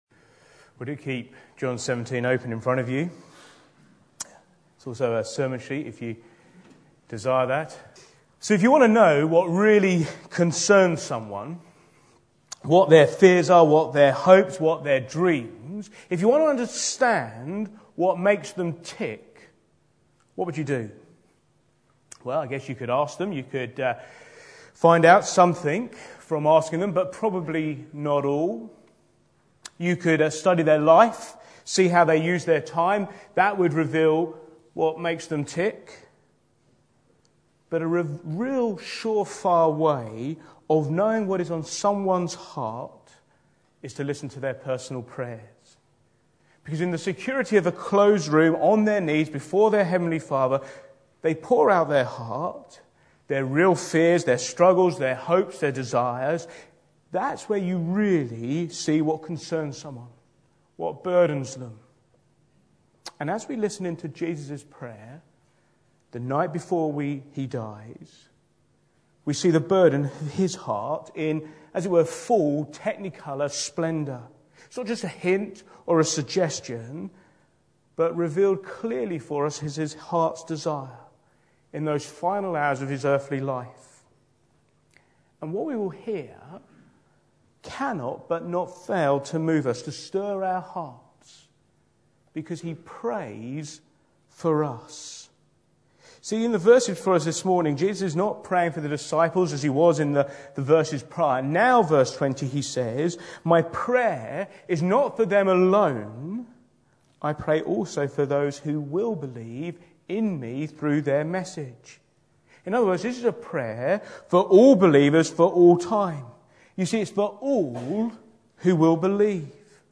Back to Sermons Father